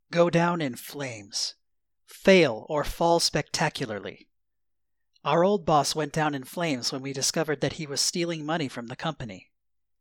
go down in flames とは、本来、飛行機が炎に包まれて落下することを言いますが、転じて完全にまたは突然に失敗したり、終焉を迎えたりするという意味のイディオムとして用いられます。 ネイティブによる発音は下記のリンクをクリックしてください。